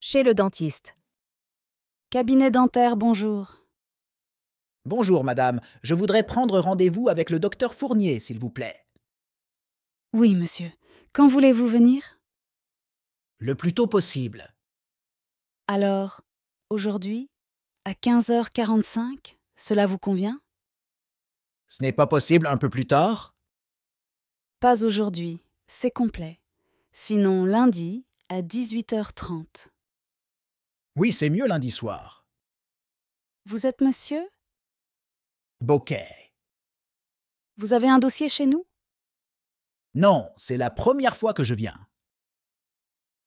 Dialogue FLE chez le dentiste